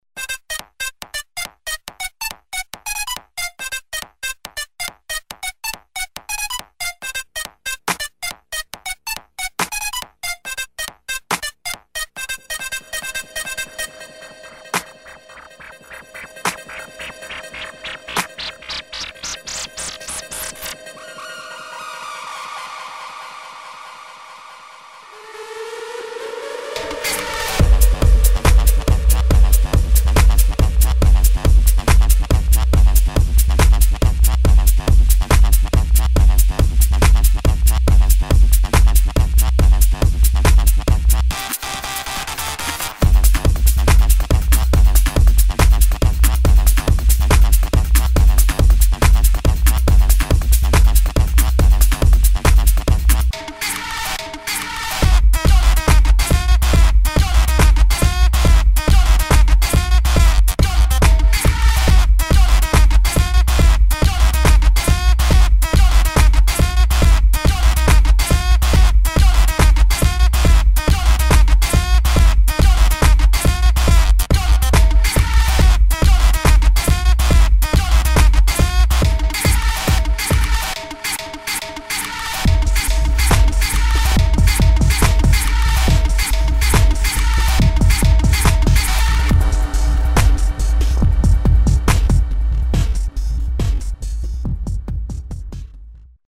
[ BREAKS / TECH HOUSE ]
バウンシー・ビートとダーティー・ベースでフロアを盛り上げる